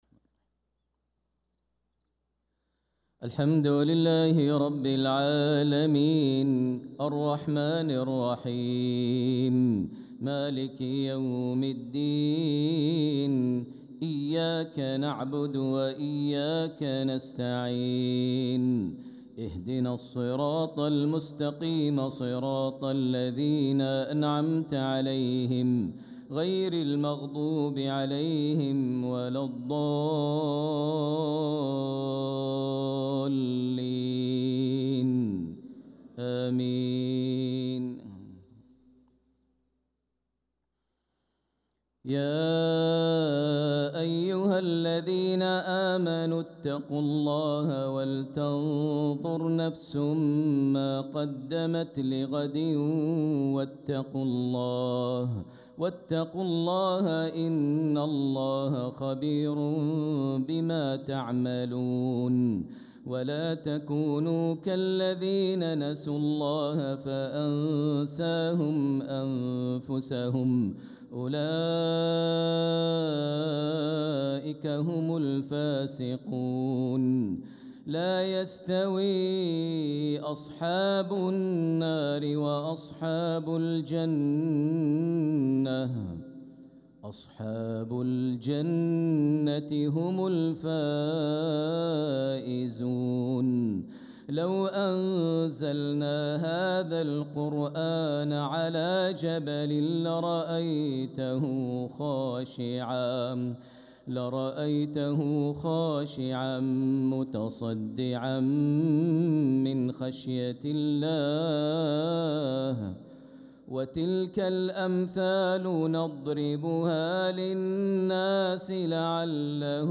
صلاة المغرب للقارئ ماهر المعيقلي 8 ربيع الأول 1446 هـ
تِلَاوَات الْحَرَمَيْن .